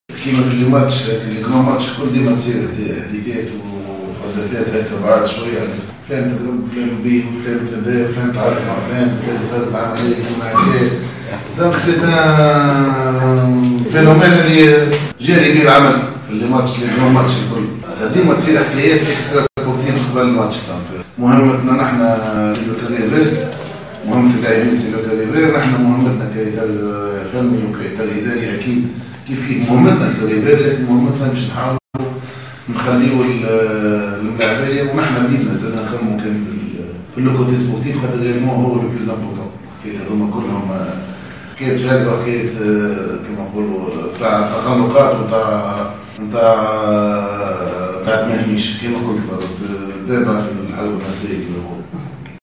أكد مدرب الملعب القابسي ماهر الكنزاري خلال الندوة الصحفية التي عقدها اليوم للحديث عن لقاء دربي قابس الذي سيجمع فريقه يوم غد الأحد بجاره المستقبل الرياضي بقابس أن جل المقابلات الكبرى والمهمة تكون عادة مسبوقة بتداول مواضيع من شأنها تشتيت تركيز الفريق الخصم في إشارة إلى التسجيل الصوتي الذي تم نشره بخصوص محاولة شراء ذمة بعض لاعبي الجليزة للتخاذل في اللقاء.